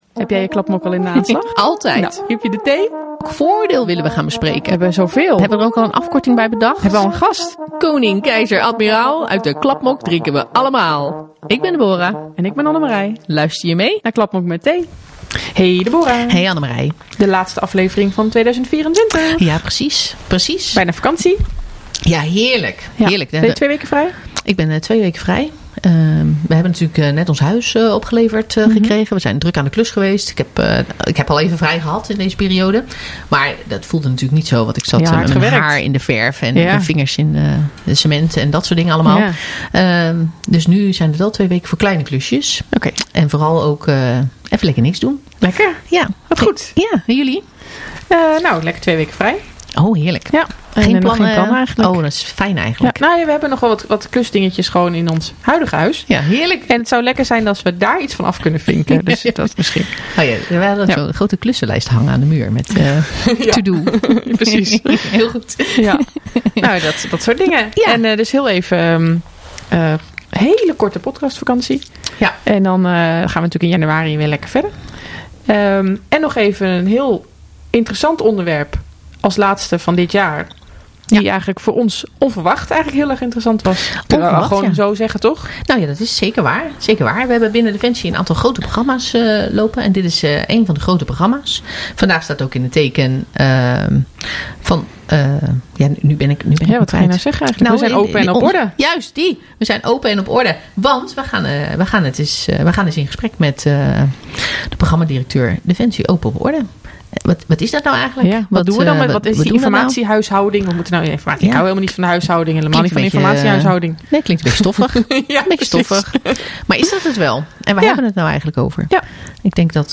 Ze discussiëren, spreken gasten en lachen vooral heel veel.
… continue reading 89 episode # Maatschappij # Conversaties # Overheid # Landmacht FM